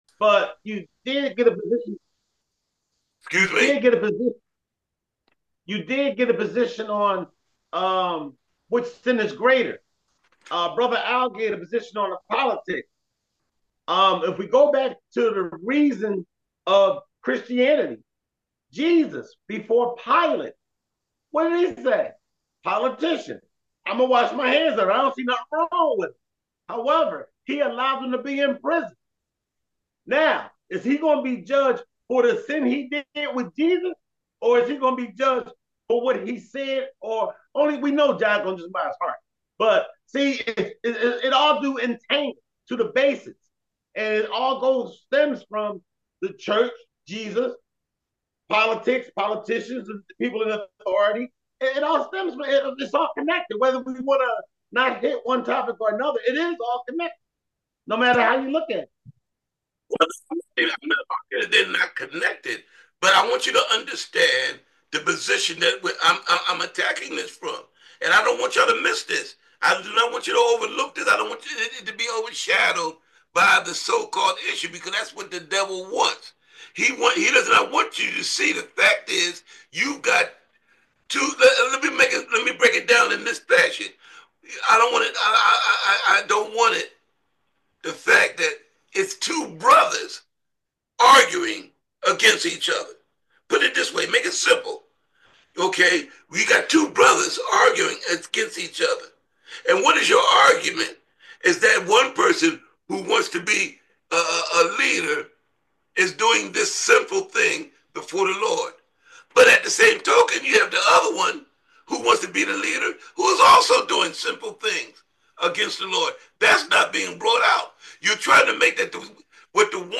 St James Bible Study – Where is the AGAPE’ Love?
bible-study_agape-love.m4a